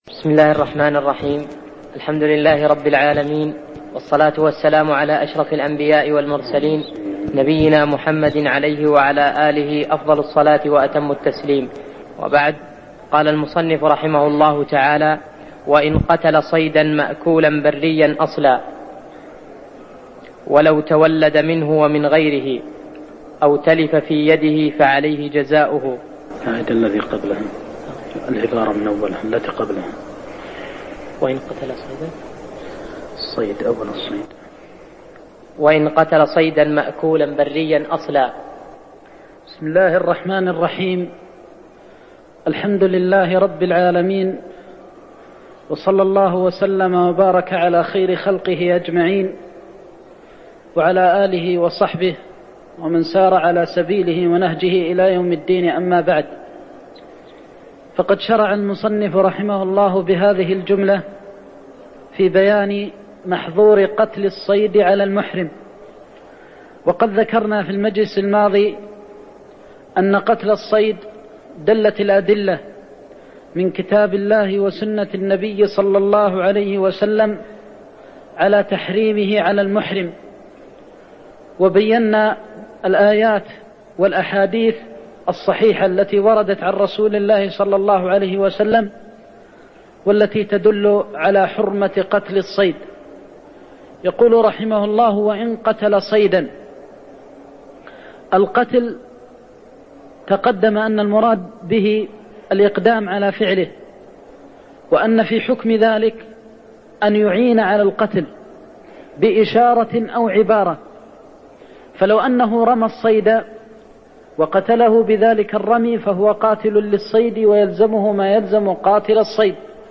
تاريخ النشر ١٩ شوال ١٤١٧ هـ المكان: المسجد النبوي الشيخ